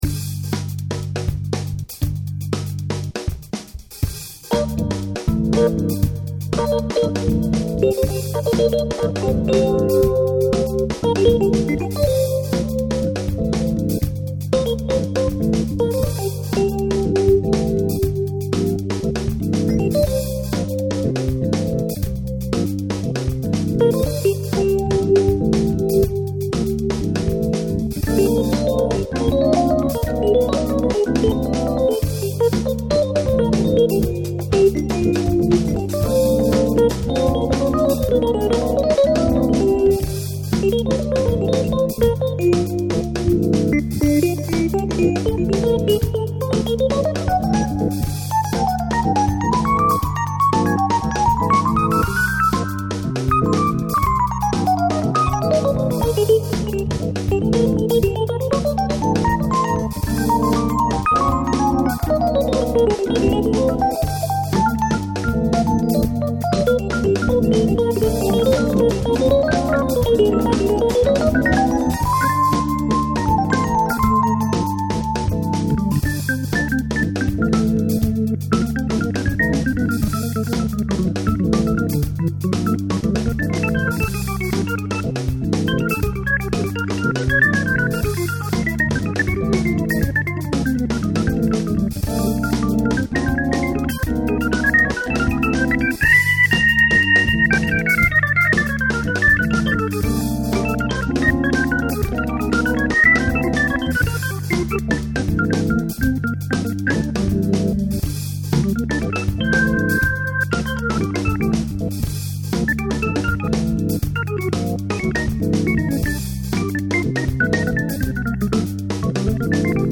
B4, Rhodes et BAR....votre avis
Un de mes vieux titres réadapté, le tout fait uniquement avec des synthés et BAR....c'est une maquette ....il y aura de vrais musicos derrière ...le cas échéant....si on enregistre ce titre....
groove :wink:
C'est pas exactement mon style, mais ça a un coté Funk 70's bien groovy.
très chouette,évidemment avec un vrai batteur ce devrait être autre chose...le son d'orgue est bon..je ne parle pas de l'interprétation..
Tiens du smooth :D ! bien groovy, j'aime bien